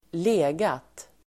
Uttal: [²l'e:gat]